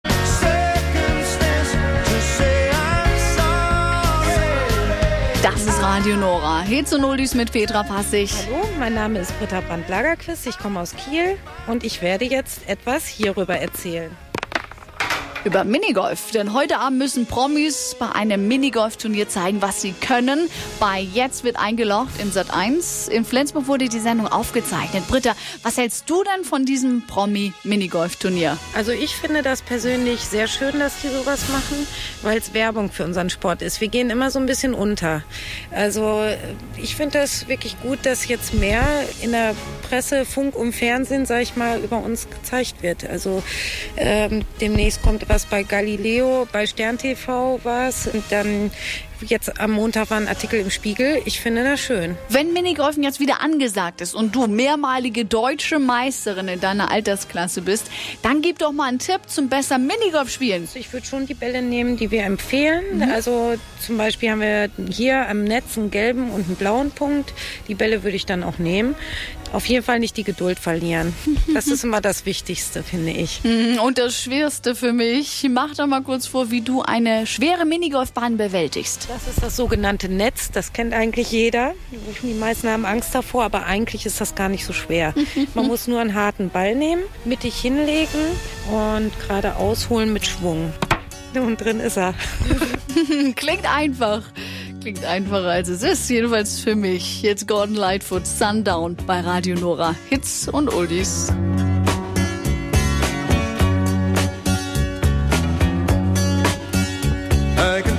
Einen Tag vor der "Sat.1" - Show "Jetzt wird eingelocht!" riefen viele große Rundfunkanstalten Minigolfer ihrer Region an, fragten sie zur Ausstrahlung der Sendung und zum Thema Minigolf allgemein.